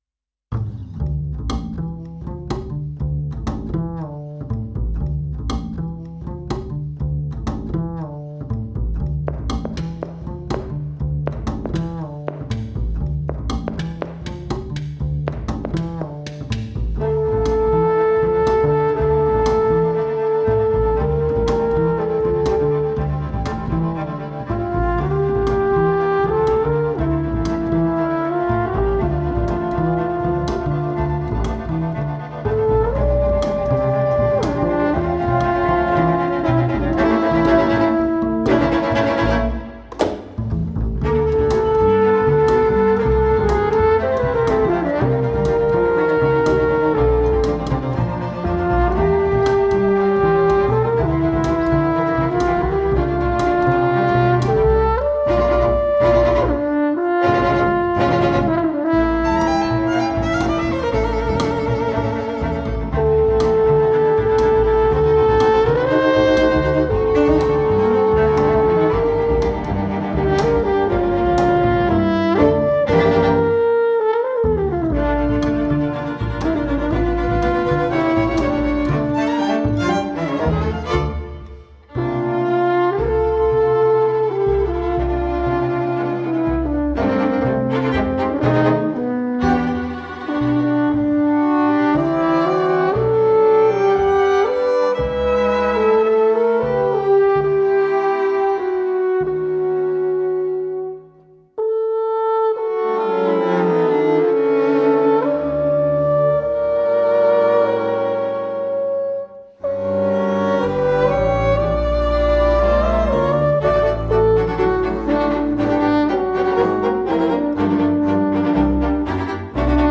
for horn and string quintet